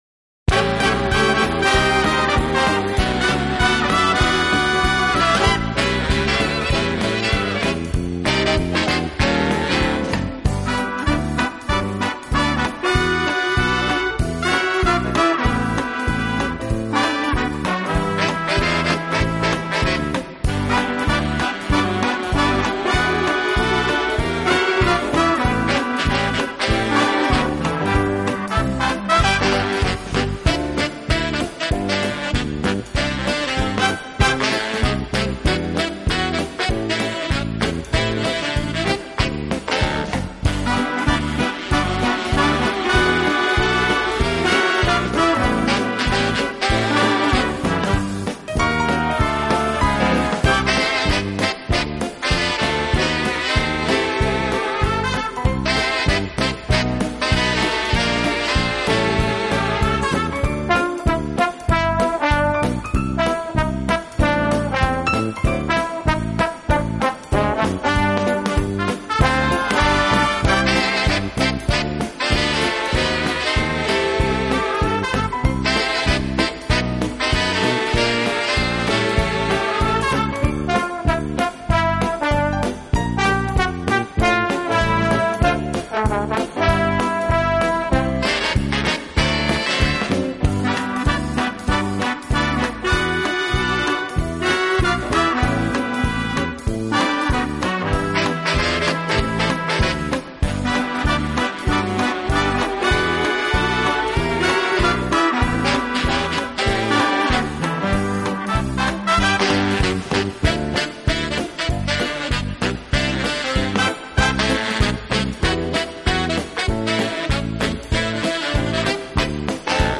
【轻音乐】
Foxtrott 48